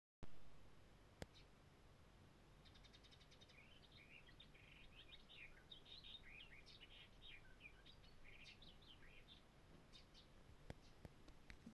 Птицы -> Славковые -> 2
ястребиная славка, Curruca nisoria
СтатусПоёт